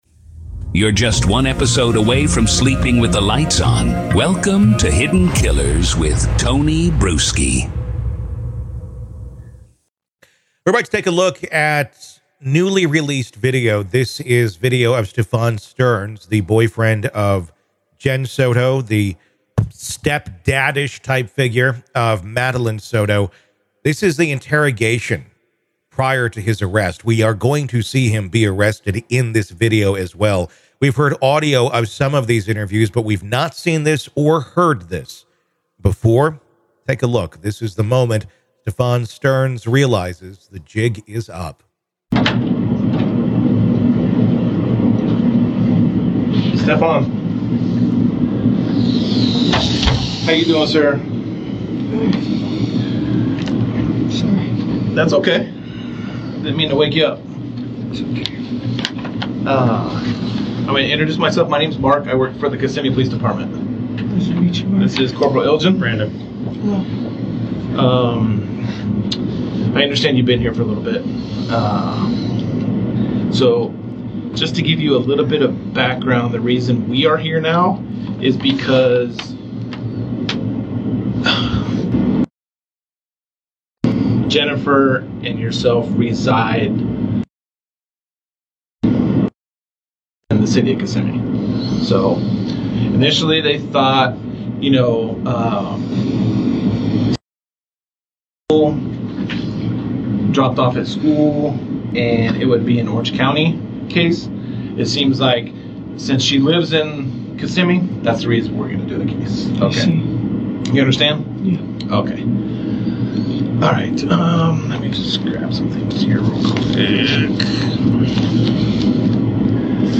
This video shows the interrogation that took place before his arrest.